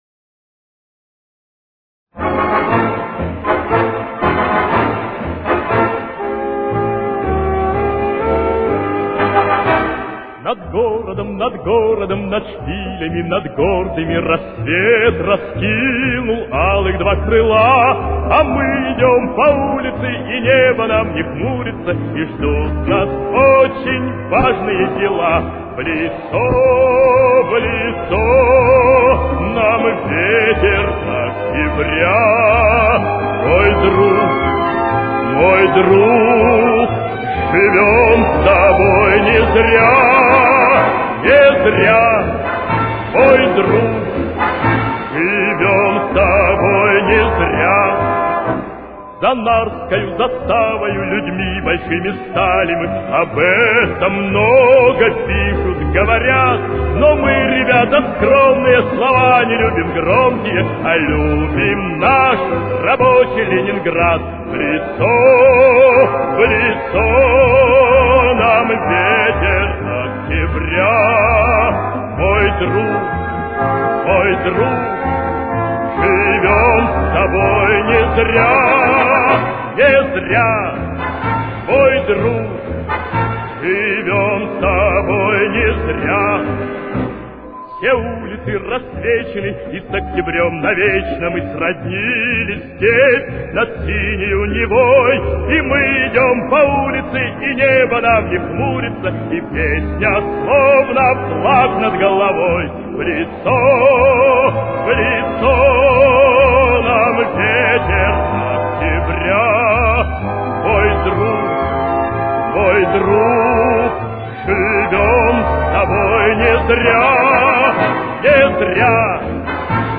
с очень низким качеством (16 – 32 кБит/с)
Темп: 125.